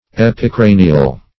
Search Result for " epicranial" : The Collaborative International Dictionary of English v.0.48: Epicranial \Ep`i*cra"ni*al\, a. (Anat.)